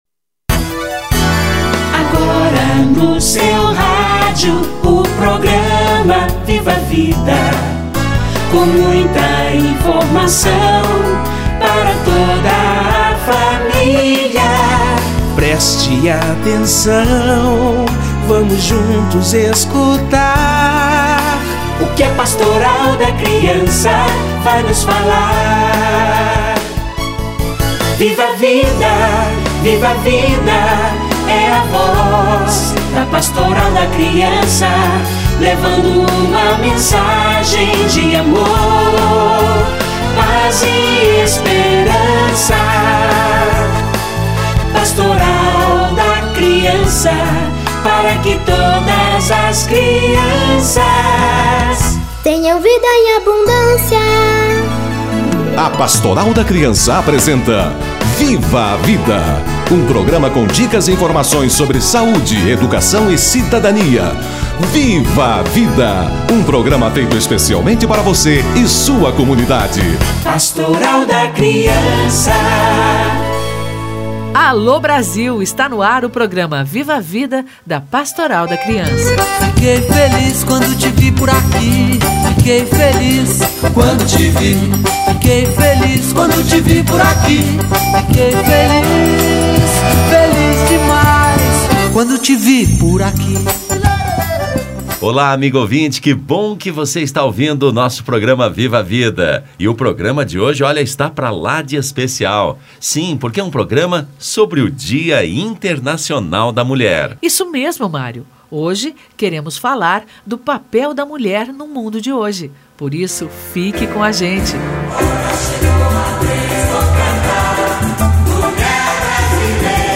Dia Internacional da Mulher - Entrevista